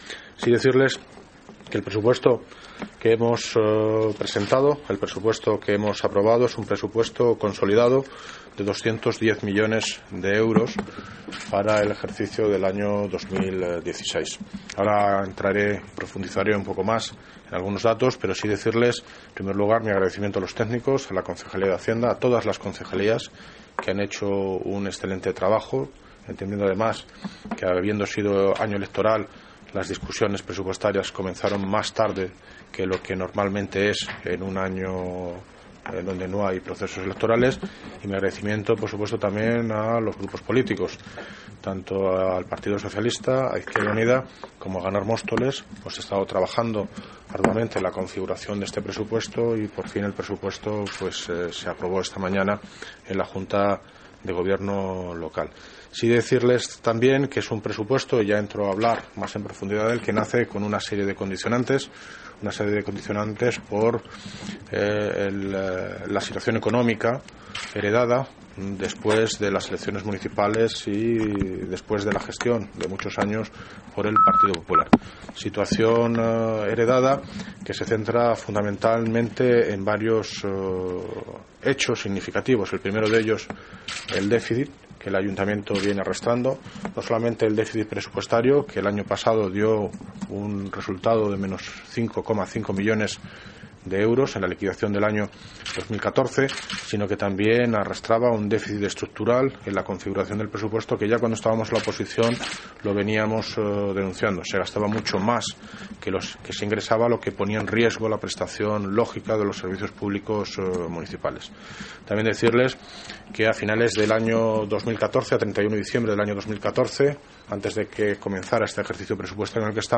Audio - David Lucas (Alcalde de Móstoles) Sobe presupuestos